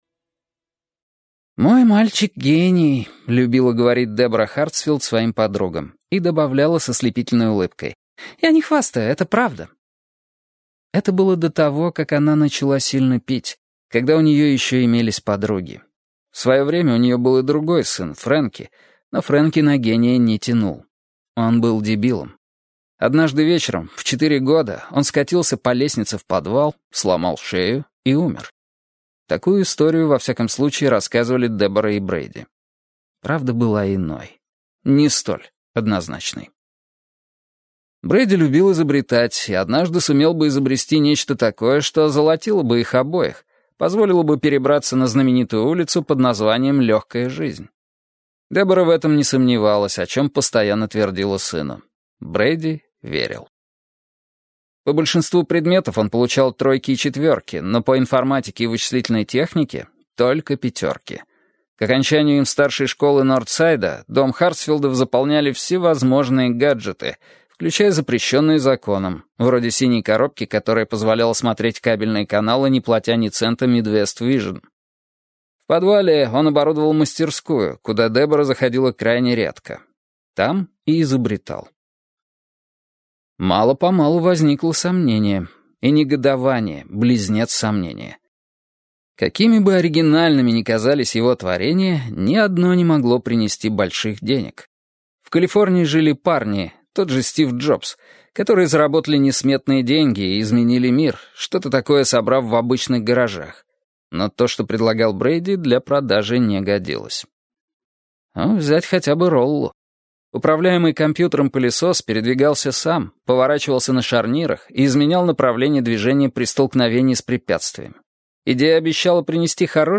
Аудиокнига Пост сдал - купить, скачать и слушать онлайн | КнигоПоиск